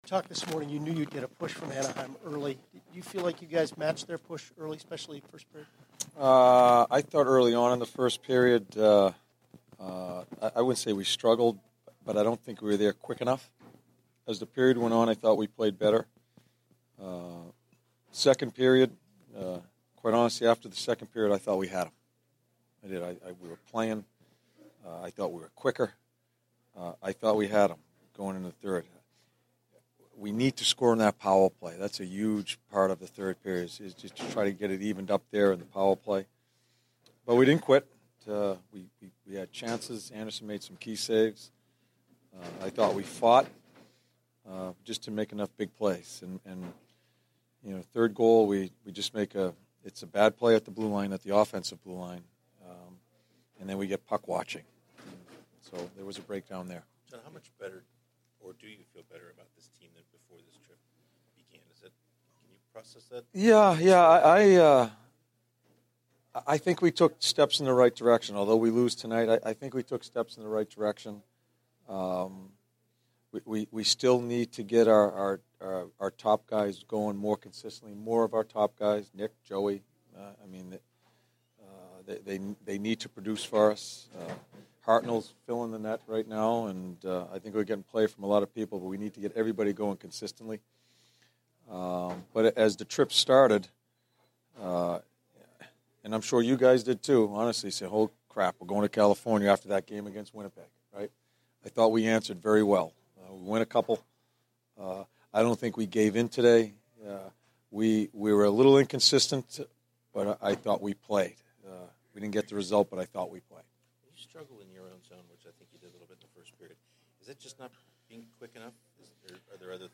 John Tortorella Post-Game 11/6/15